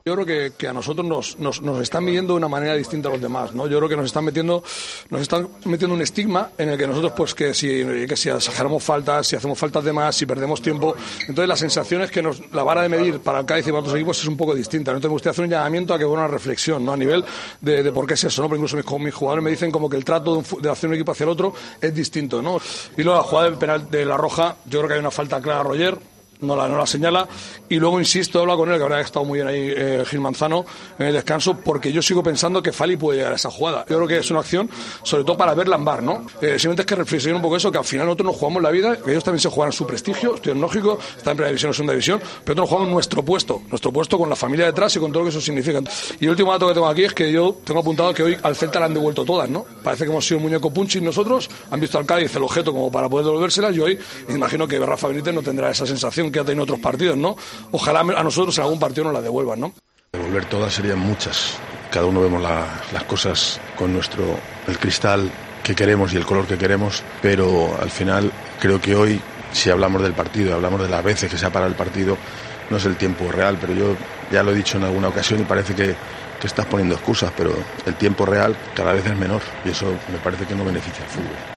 Escucha las declaraciones de los dos entrenadores tras el Celta - Cádiz.